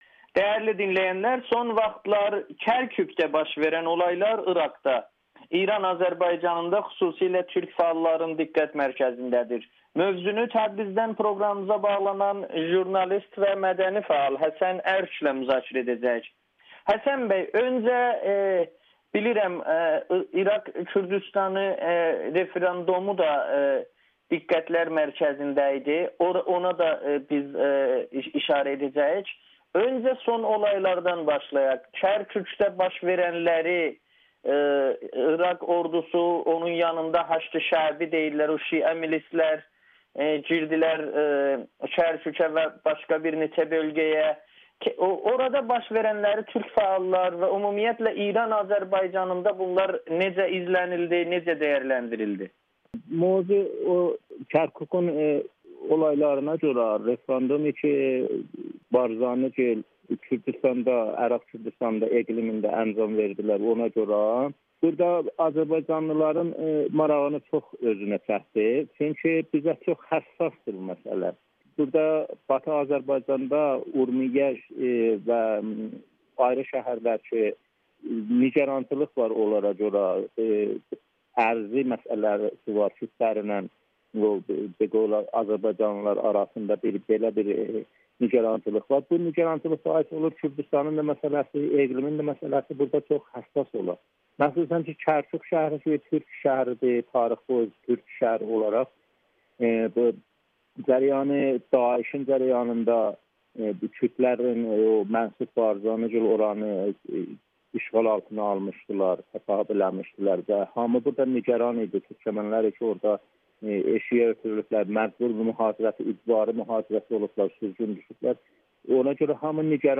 Kərkükdə baş verənlər İran Azərbaycanında da diqqət mərkəzindədir [Audio-Müsahibə]